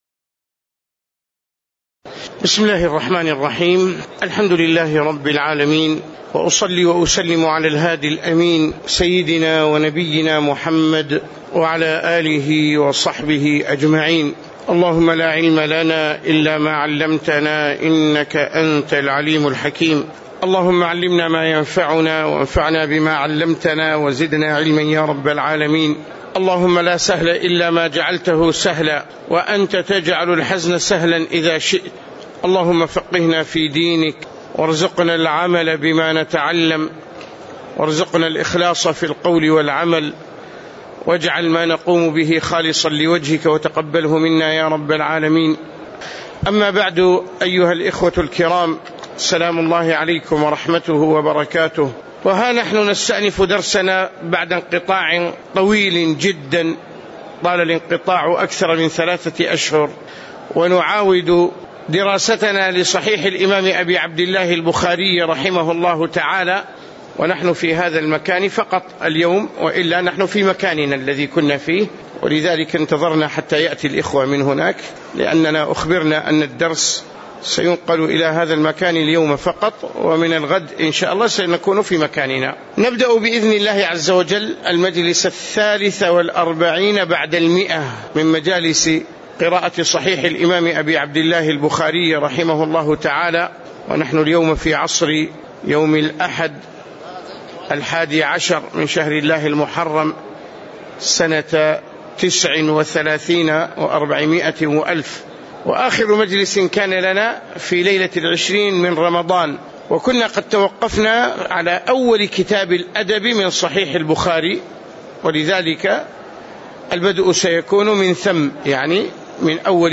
تاريخ النشر ١١ محرم ١٤٣٩ هـ المكان: المسجد النبوي الشيخ